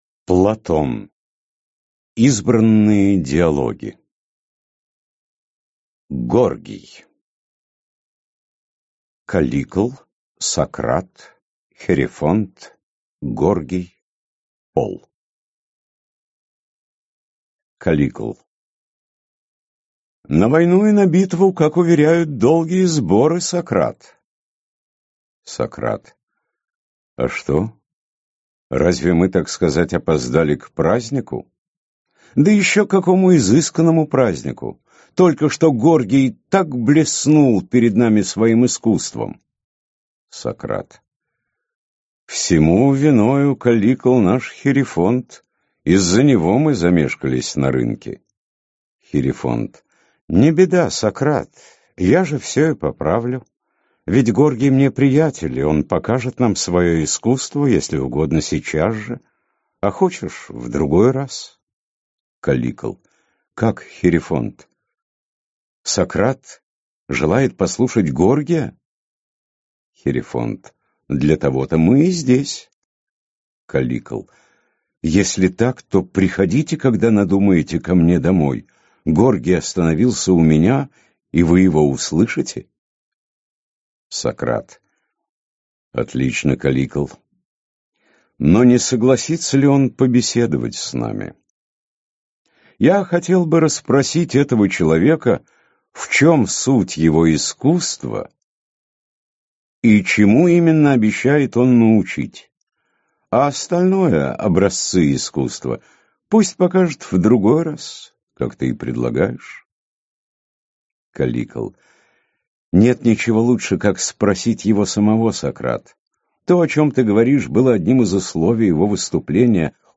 Аудиокнига Горгий. Федон | Библиотека аудиокниг